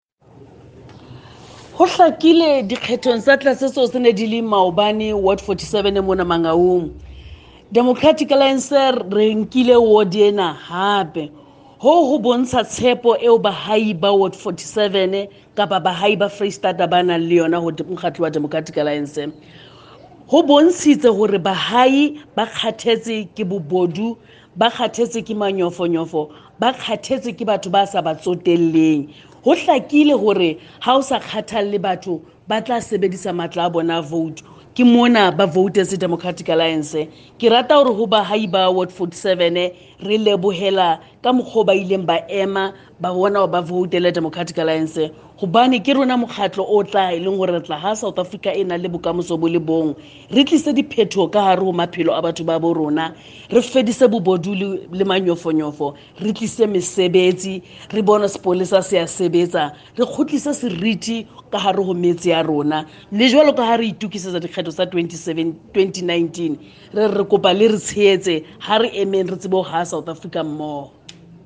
Find attached soundbites in